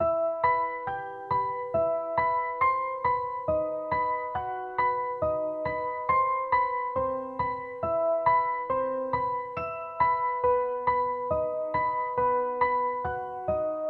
飞行员影子钢琴
描述：添加你自己的混响
Tag: 138 bpm Dubstep Loops Piano Loops 2.34 MB wav Key : E